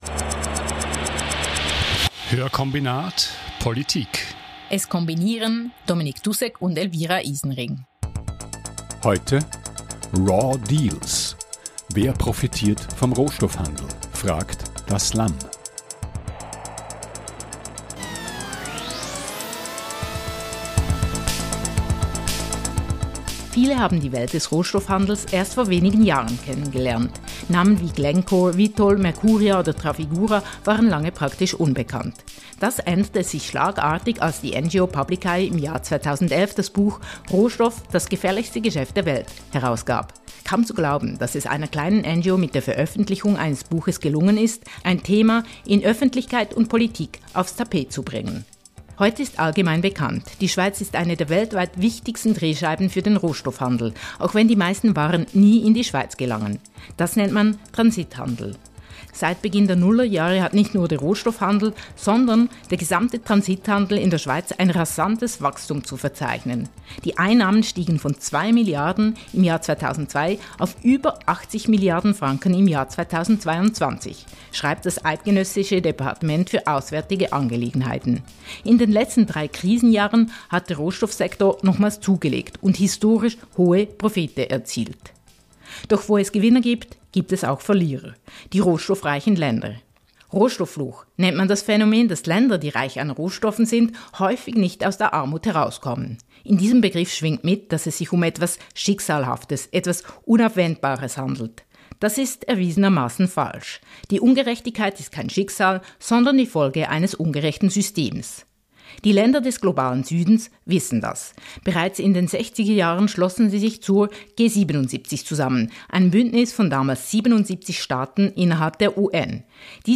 In jeder Folge bespricht das Audio-Duo Hörkombinat einen aktuellen Artikel mit einem/einer Journalist:in und ergänzt das Interview mit Hintergrundinformationen. Der Schwerpunkt liegt auf sozial- und wirtschaftspolitischen Themen.